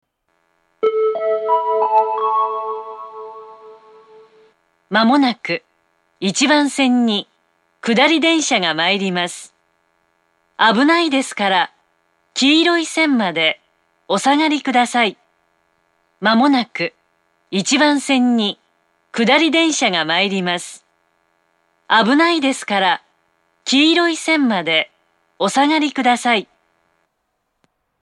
２０１２年１２月中旬に放送装置が更新され、音質が向上し、メロディーの音程が下がりました。
１番線接近放送
１番線発車メロディー 曲は「Gota del Vient」です。音程は低いです。